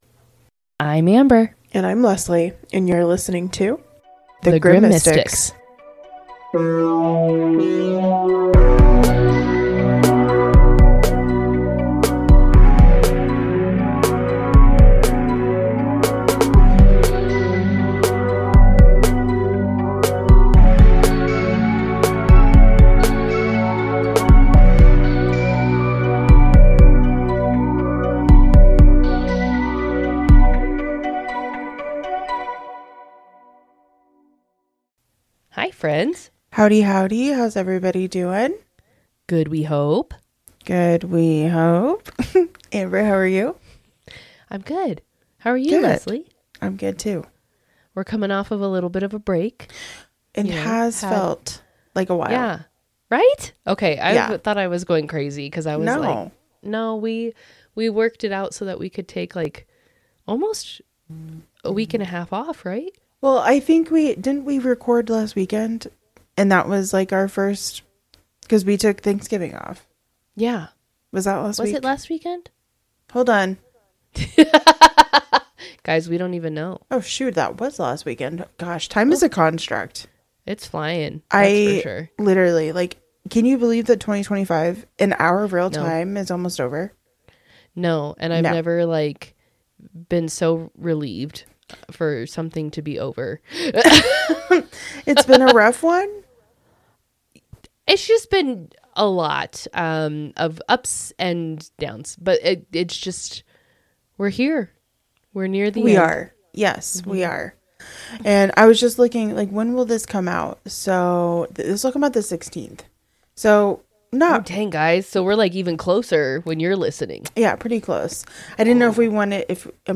It’s spooky, it’s historical, and it’s delivered with plenty of laughs, side commentary, and mild concern for everyone involved 🥴